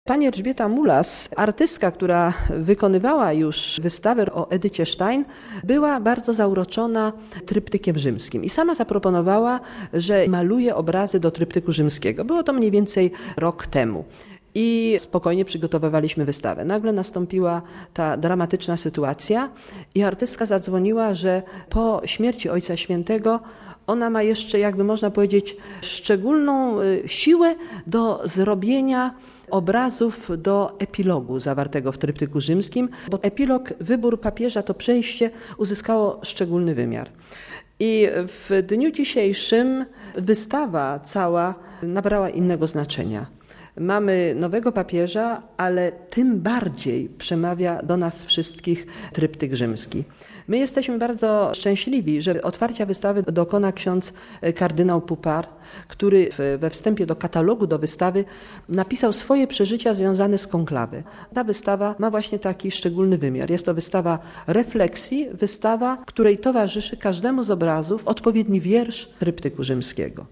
Tryptyk Rzymski. Pamięci Jana Pawła II - wystawą pod tym tytułem Ambasada Polska przy Stolicy Apostolskiej uczciła 85. rocznicę urodzin zmarłego papieża. Mówi ambasador Hanna Suchocka.